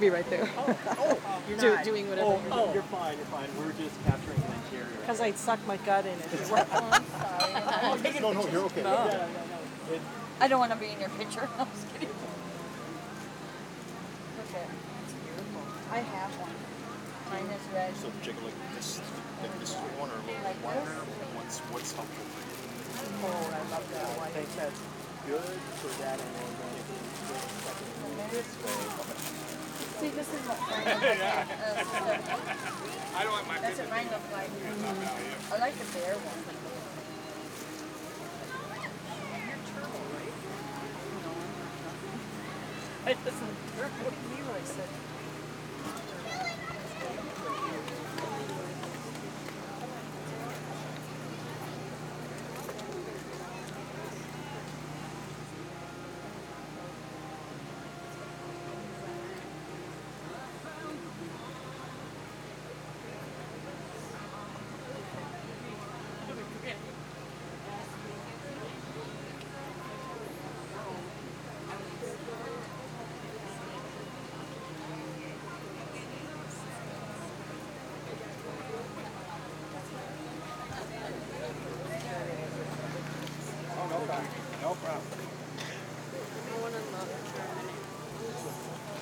Menominee POWWOW